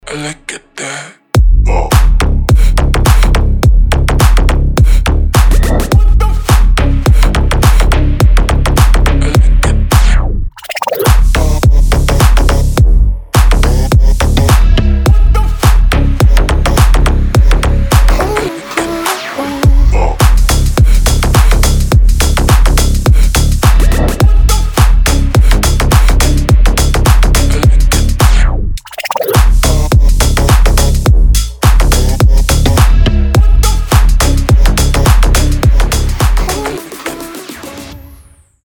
Стиль: G-house